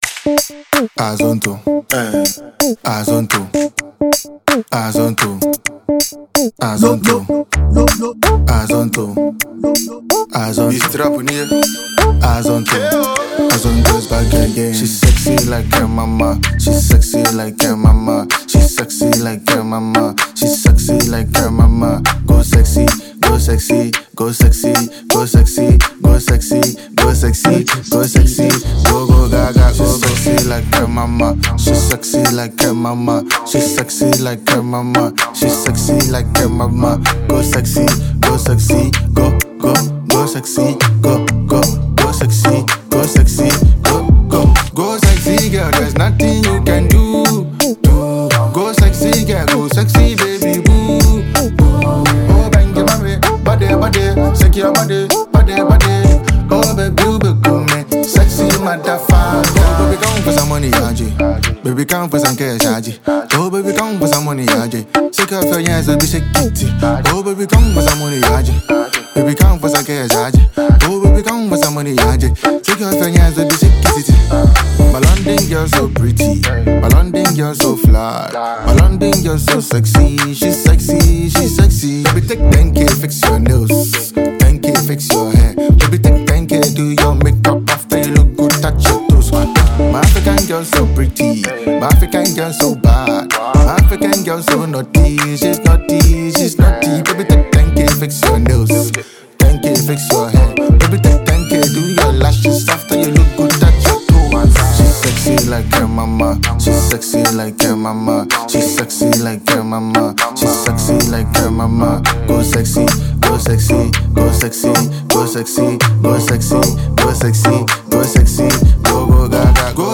a Ghanaian trapper
Enjoy this magical production from this rapper.